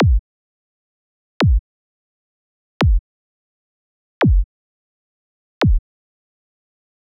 Kicks:
Deomo-5-Kicks-FULL-POWER-PACK.wav